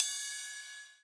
Index of /neuro/Optiv & BTK/Drums - One Shots
Ride (4).wav